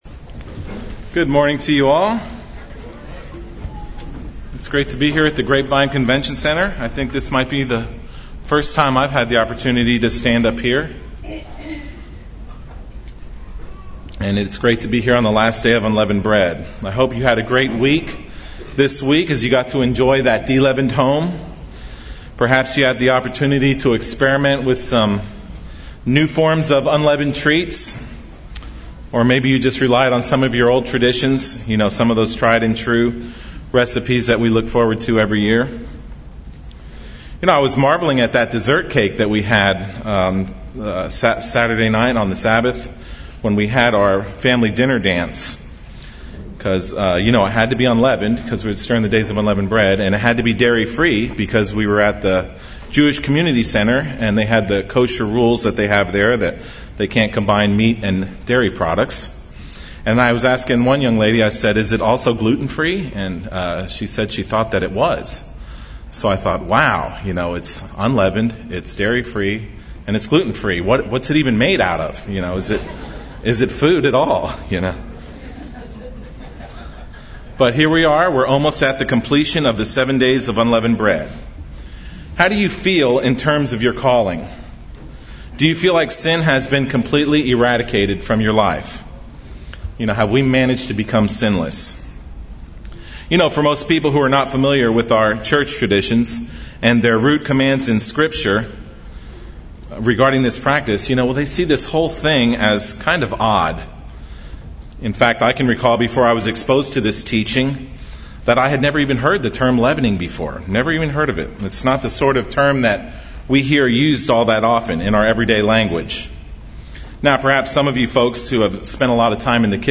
This sermon will answer the criticism that the symbolic use of deleavening to remove sin from our lives using our own efforts is an exercise in futility.
Given in Dallas, TX